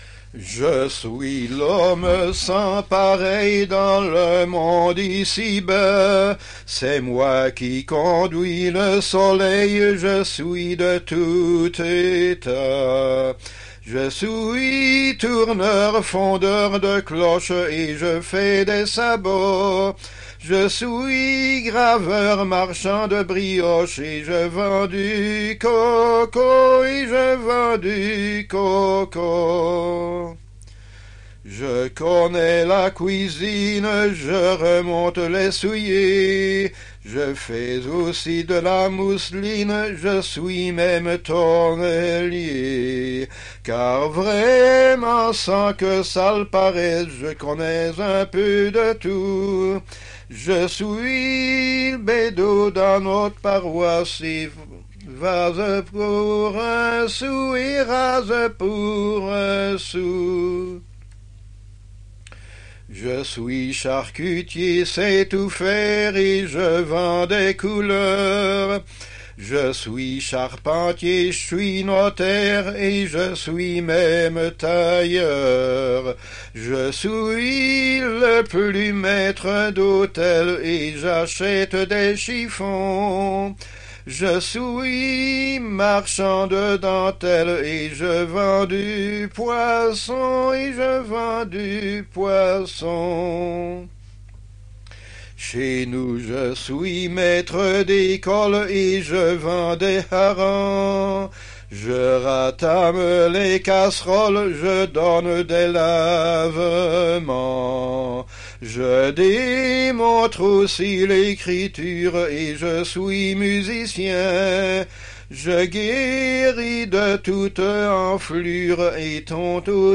Découvrez notre collection d'enregistrements de musique traditionnelle de Wallonie
Type : chanson narrative ou de divertissement Aire culturelle d'origine